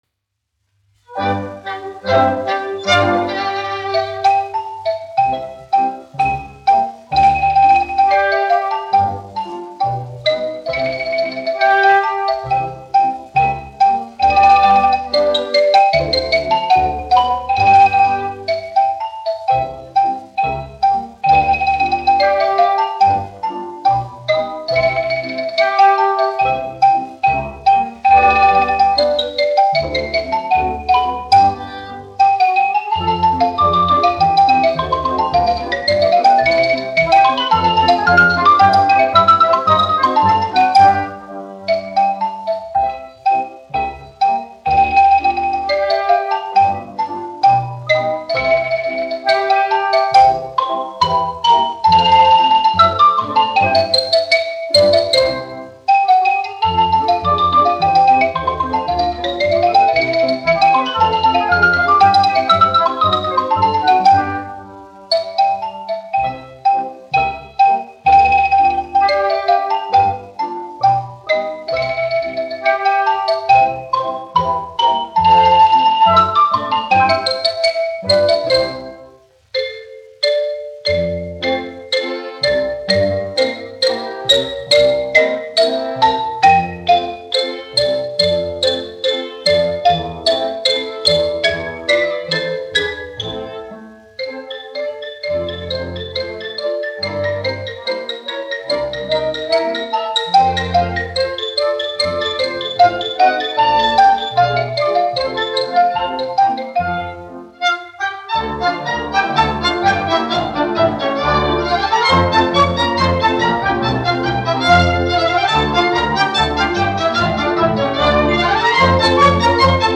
1 skpl. : analogs, 78 apgr/min, mono ; 25 cm
Ksilofons ar orķestri